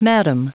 Prononciation du mot madam en anglais (fichier audio)
Prononciation du mot : madam